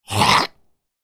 Cartoon Chomp Bite Sound Effect
This funny, goofy, cartoon-style bite sound effect adds humor, silly charm, and playful crunch to any project.
Cartoon-chomp-bite-sound-effect.mp3